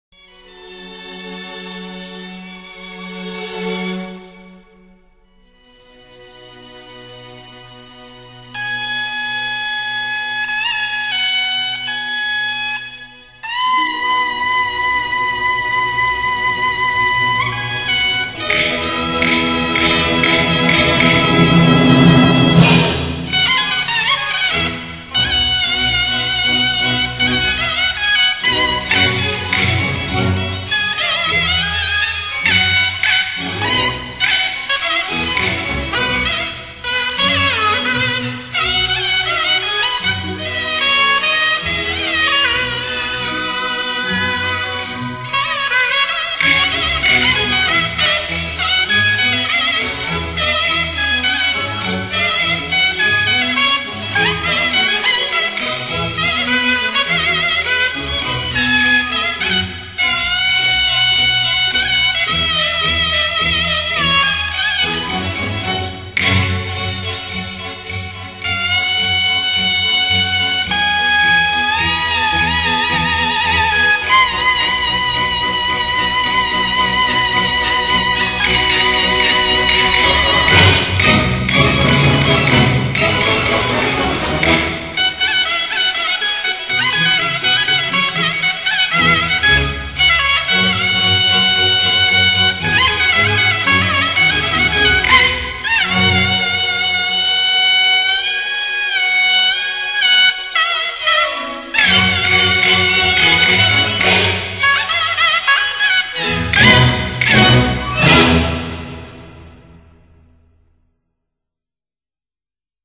京胡，三弦，月琴